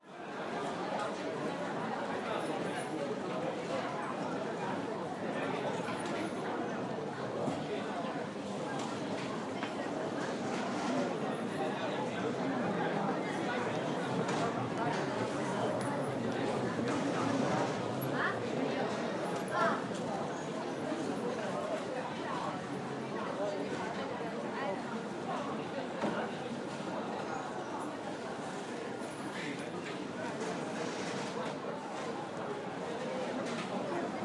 机场 " 中国国际机场(Aeroport Chinois embarq(st))
Tag: 机场 氛围 中国 大厅